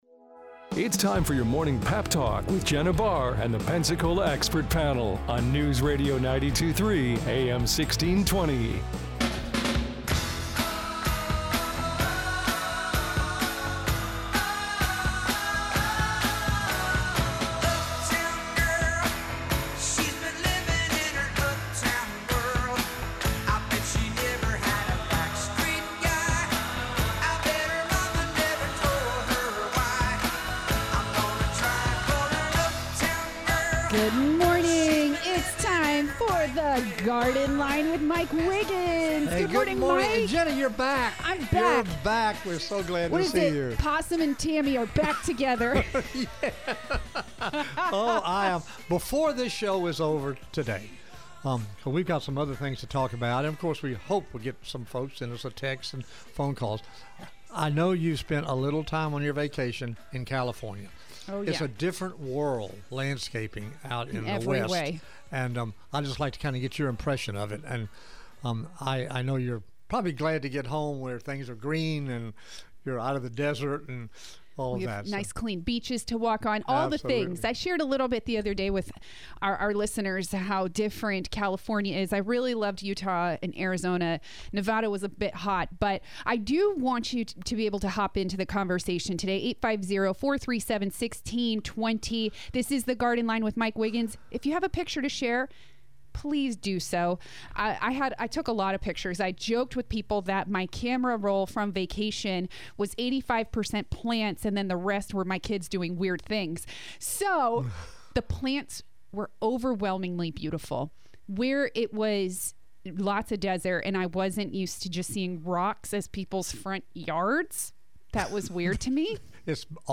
He talks watering and when NOT to fertilize your lawn. And listeners call in with their questions!